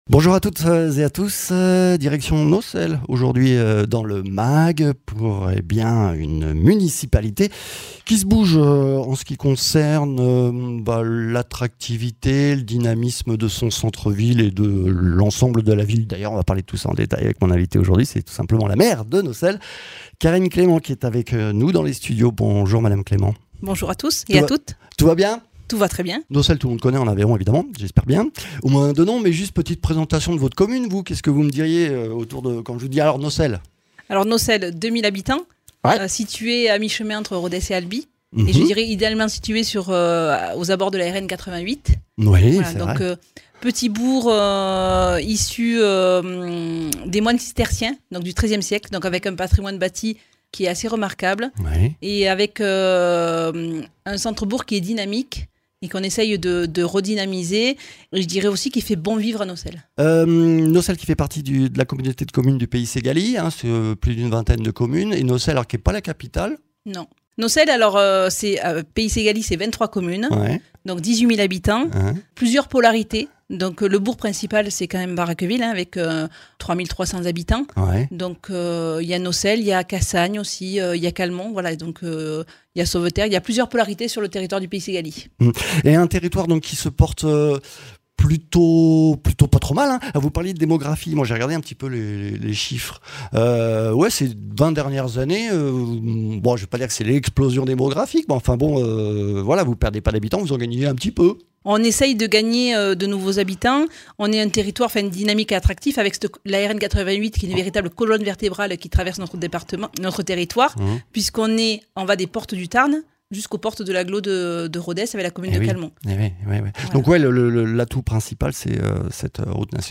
Invité(s) : Karine Clément, maire de Naucelle